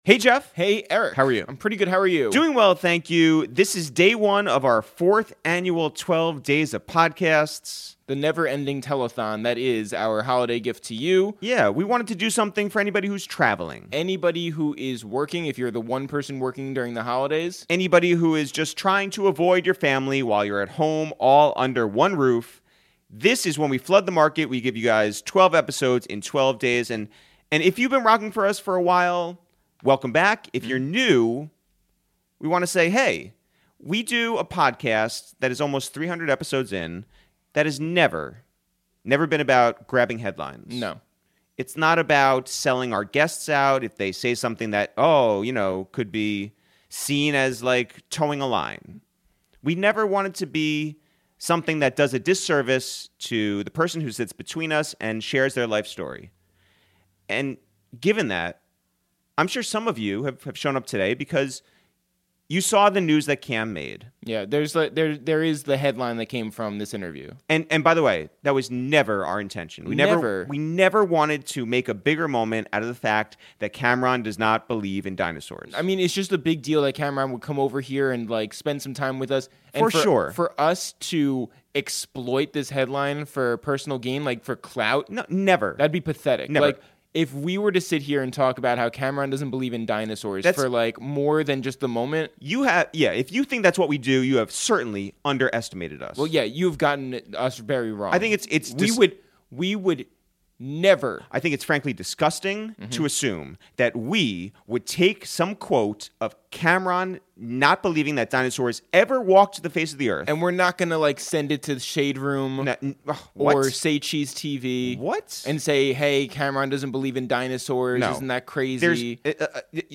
Today on A Waste of Time with ItsTheReal, for Day 1 of the #12DaysOfPodcasts, we welcome Harlem legend Cam'Ron to the Upper West Side for a wide-ranging, dynamic, hilarious and personal conversation!